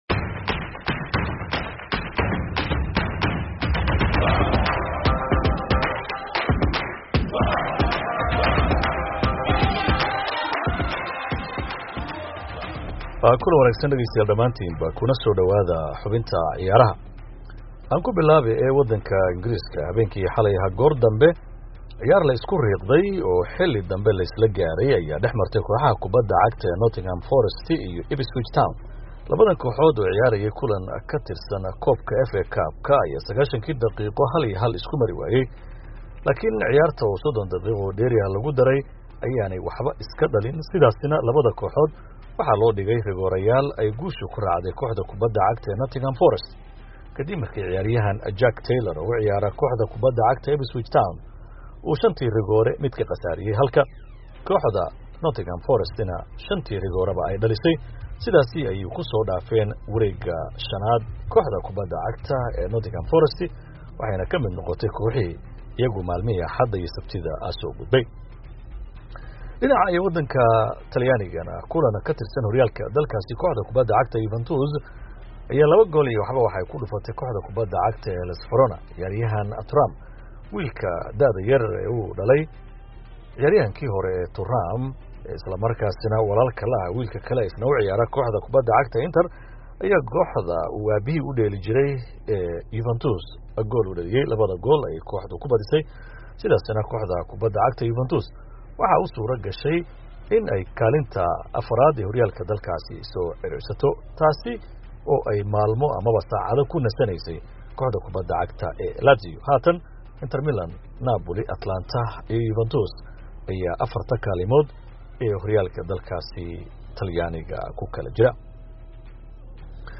Wararkii ugu dambeeyay ee ciyaaraha